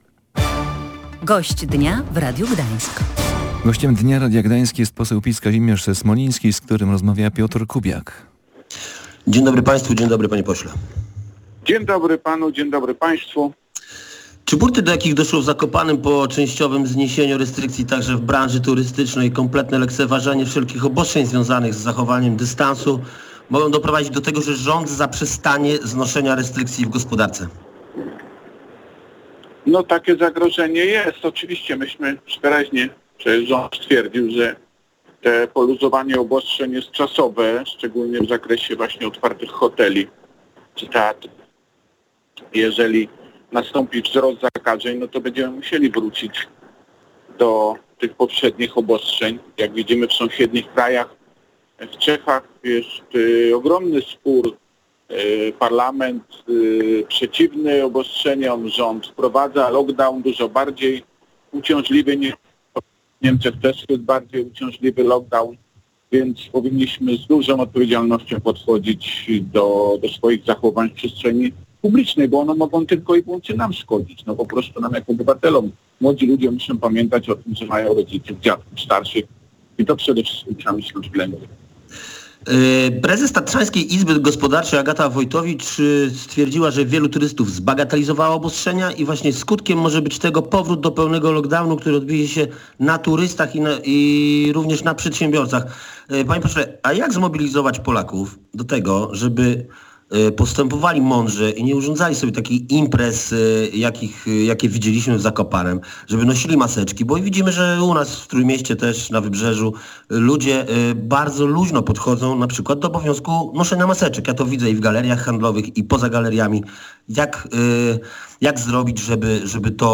Zjednoczona Prawica musi przetrwać, bo nie ma alternatywy na inną koalicję rządową – mówił w Radiu Gdańsk poseł Prawa i Sprawiedliwości Kazimierz Smoliński.